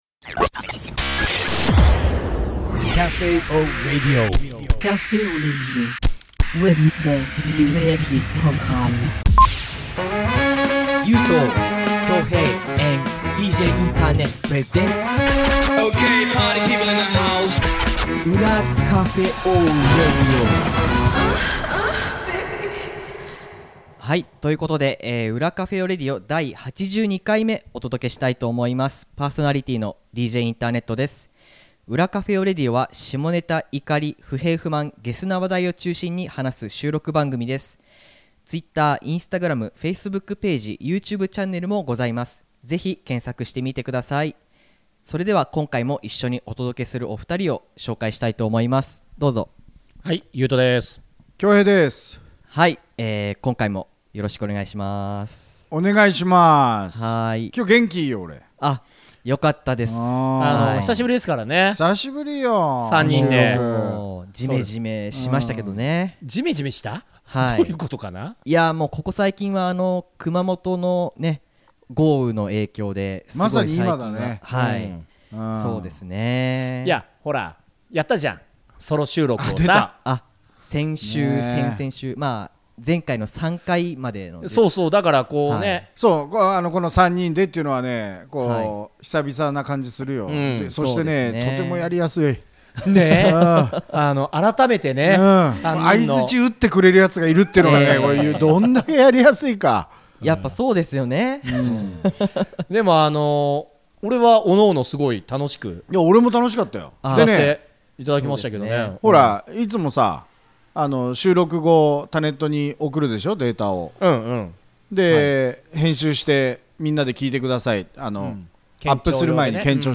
裏Cafe au Radio 久々に3人で収録です。 今回はソロトーク企画中にリスナーから送られた 怒りのメッセージを紹介。